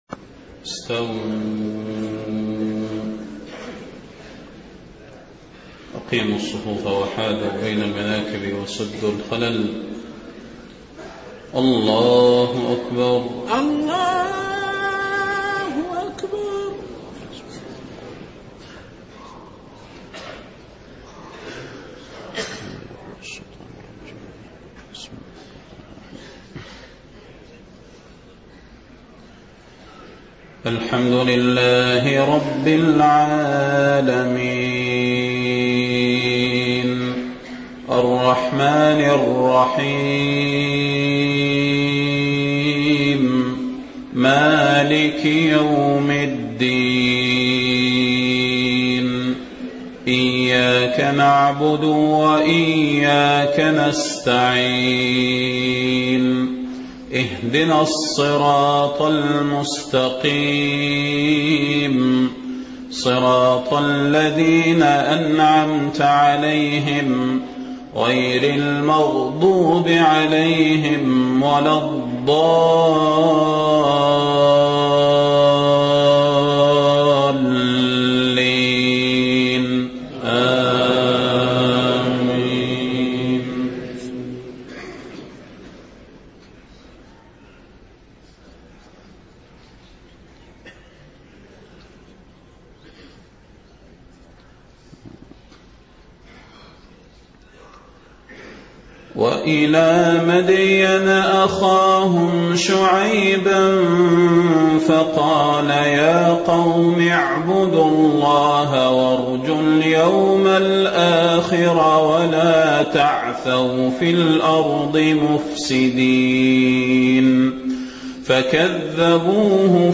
صلاة الفجر 9 محرم 1430هـ من سورة العنكبوت 36-51 > 1430 🕌 > الفروض - تلاوات الحرمين